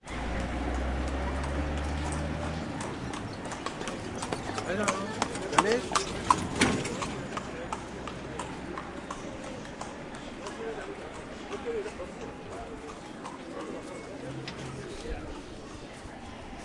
描述：一辆马车从左边驶来。车夫看到我，问我是否要搭车。他继续经过我身边。马车在路上撞了一下，然后渐渐向右移开。有一点交通噪音和说话声（可能是柏柏尔人）。摩洛哥塔鲁丹，2006年2月。索尼MZN10 MD和MS907立体声麦克风。
标签： 托架 现场记录 你好 摩洛哥 街道
声道立体声